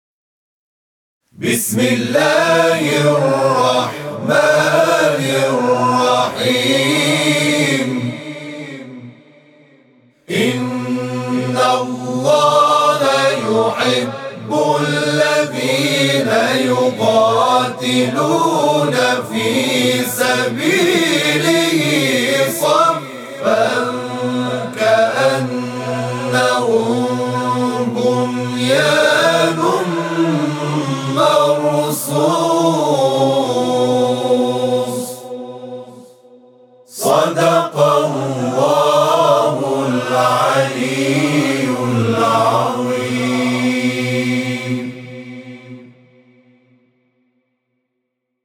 tanda nama: kumpulan Nasyid muqawamah media seni Islam